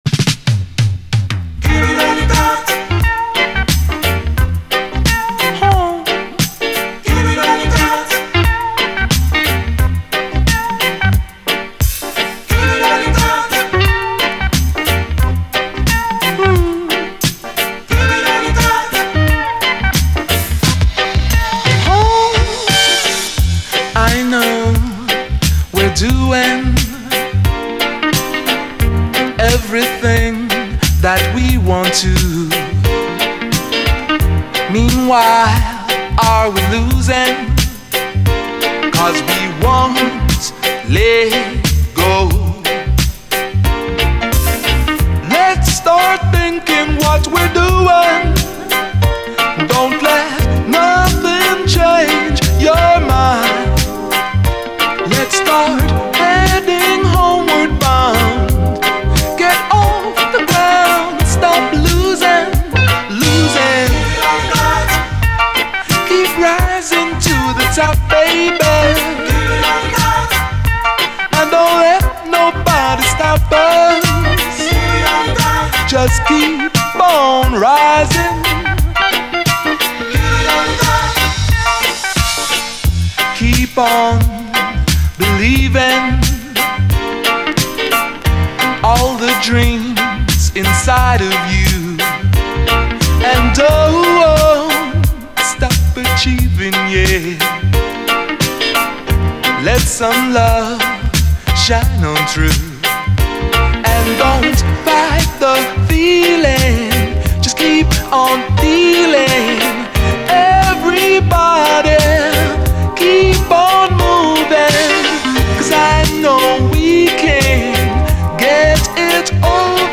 REGGAE
クソカッコいいキラー・ディスコ・レゲエです。